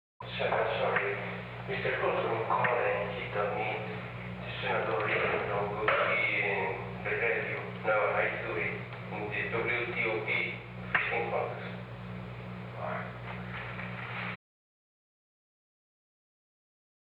Conversation: 757-009
Recording Device: Oval Office
Location: Oval Office